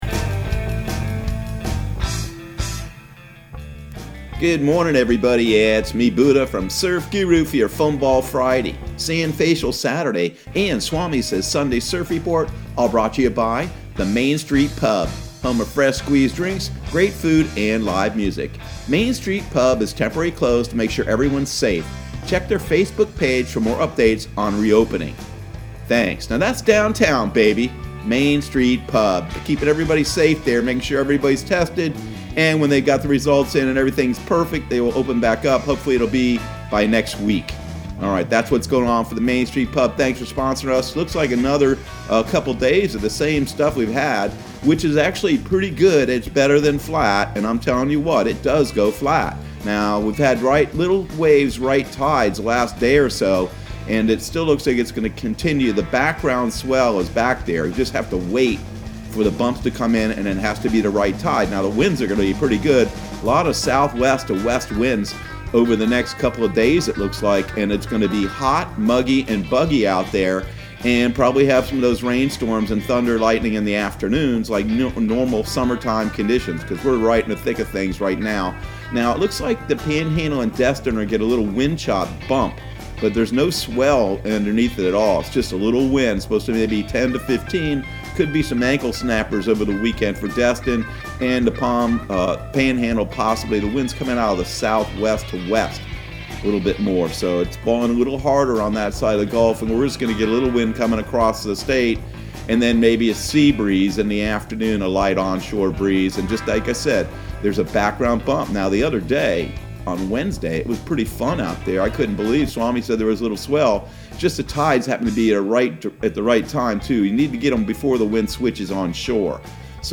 Surf Guru Surf Report and Forecast 07/03/2020 Audio surf report and surf forecast on July 03 for Central Florida and the Southeast.